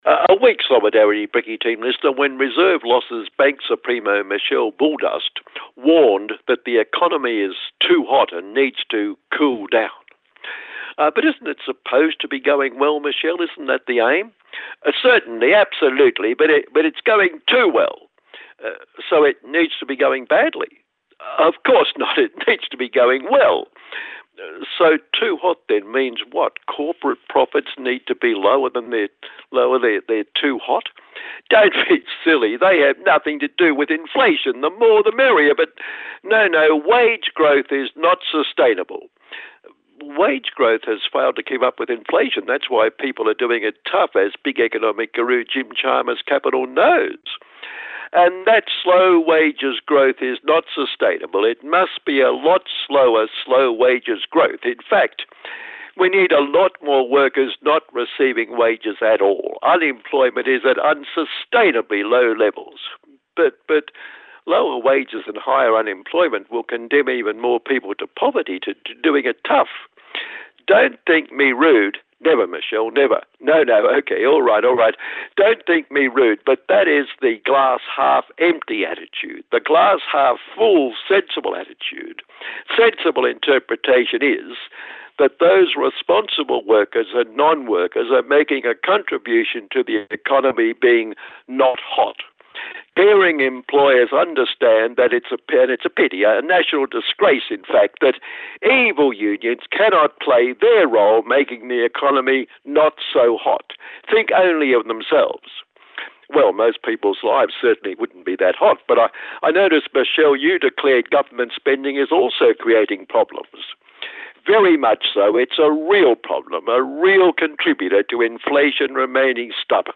Voices @ Sydney Palestine Rally here II